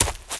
High Quality Footsteps
STEPS Leaves, Run 04.wav